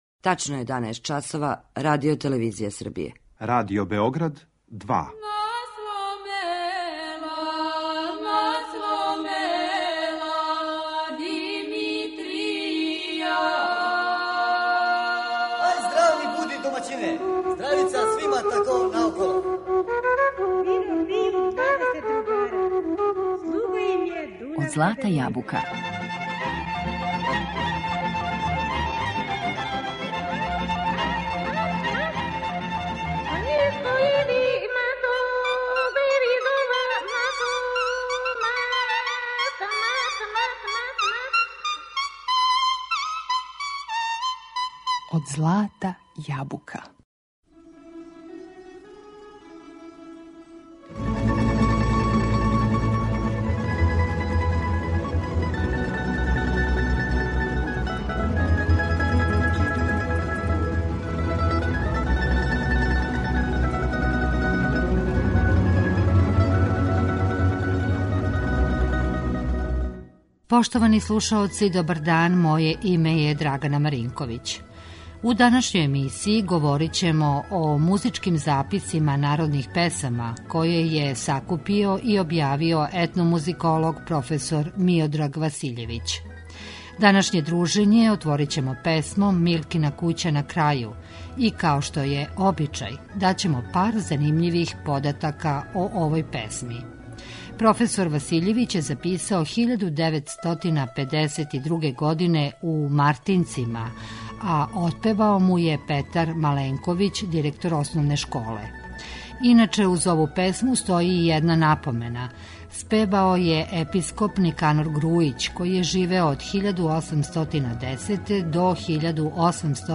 Народне песме из Војводине